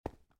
stonestep6.ogg